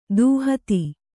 ♪ dūhati